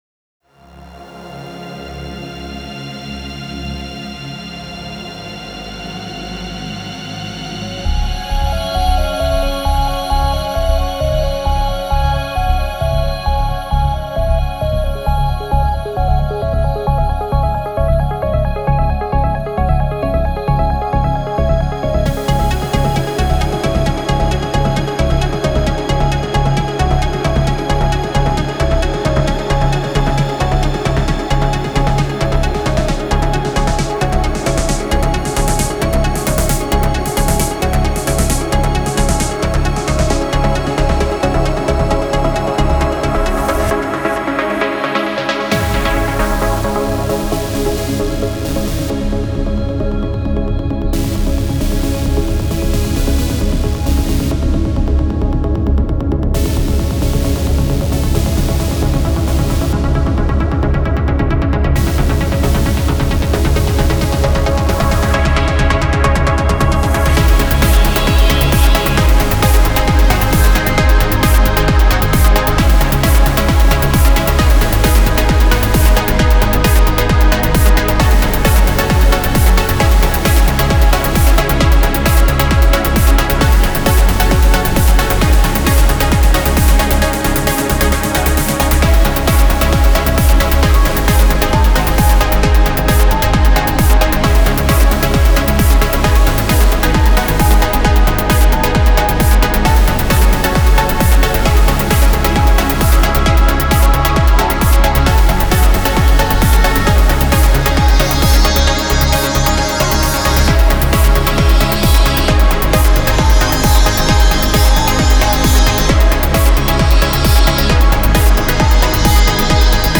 BEST ELECTRO A-F (35)